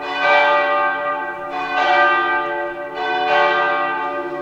Churchbells1
ChurchBells1.wav